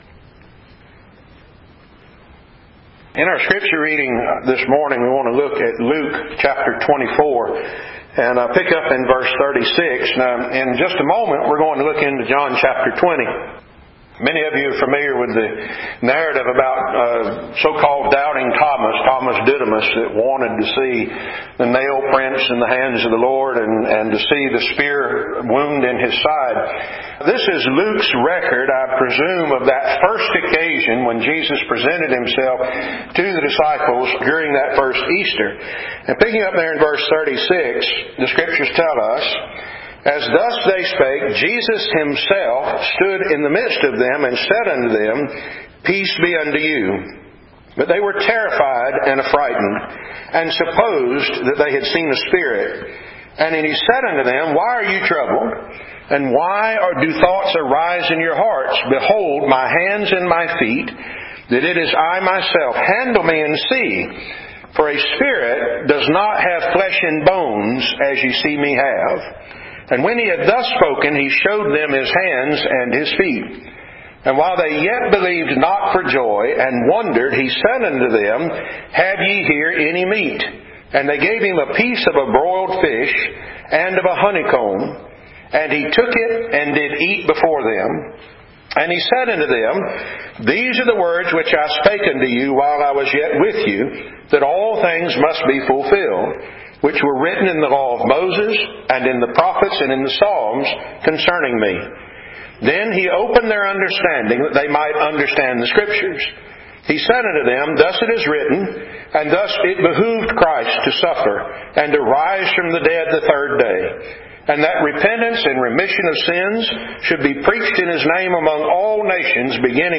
Luke 24:36-51, A Fish and a Honeycomb Apr 28 In: Sermon by Speaker